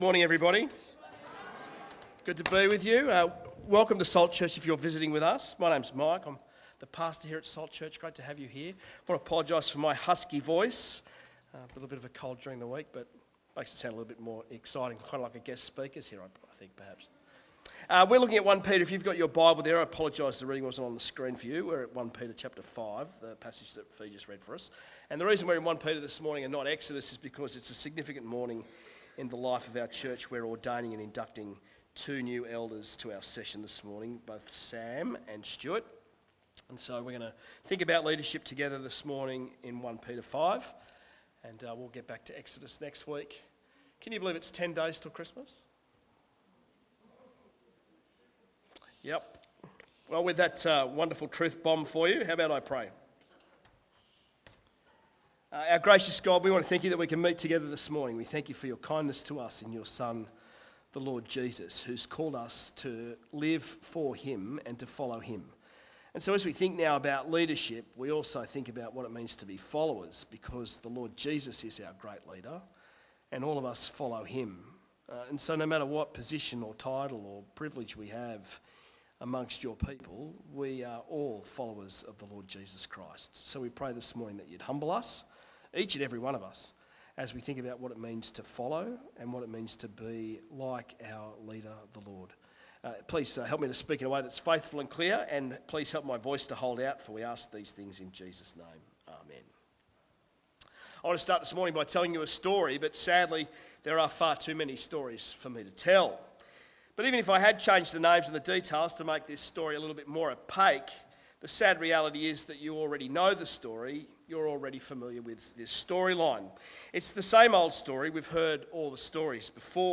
Sermons
One off Talk from 1 Peter 5. For induction of new elders.